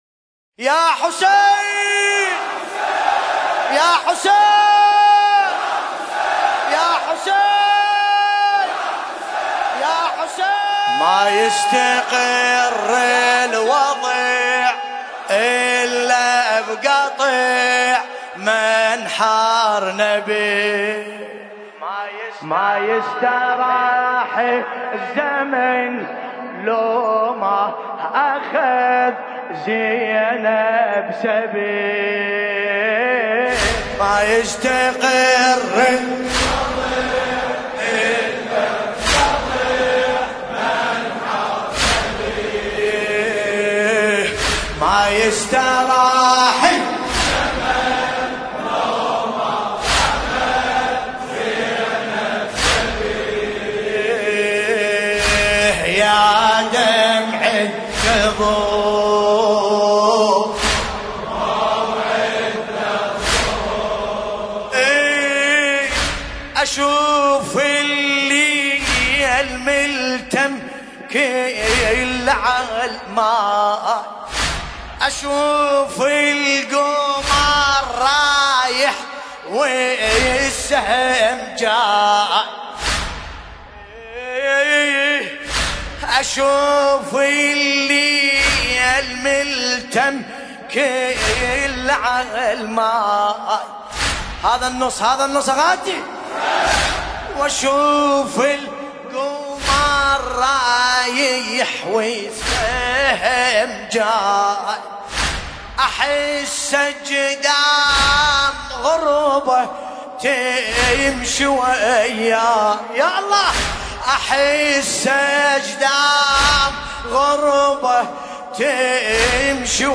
ملف صوتی ما يستقر الوضع بصوت باسم الكربلائي